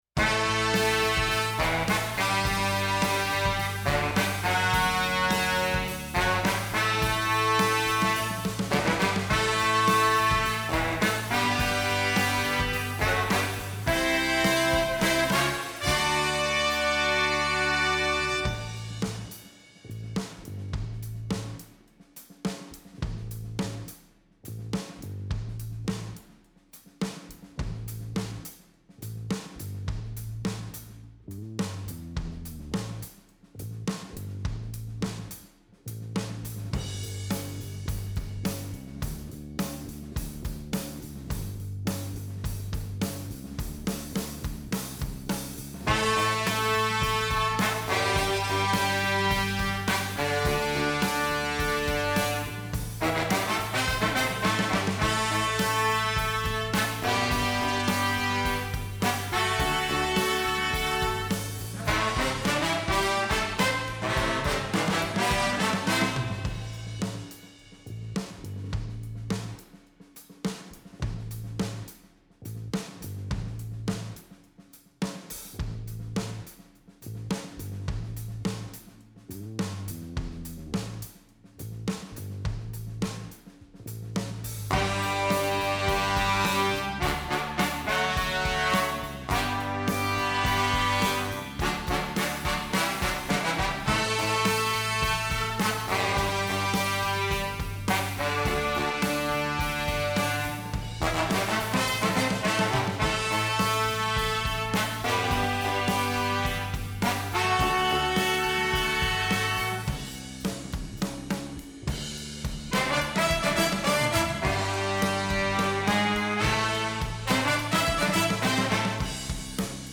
(horns/bass/drums)
Here’s a heavily stripped-down mix putting the horns
on tenor and baritone sax
trumpet
trombone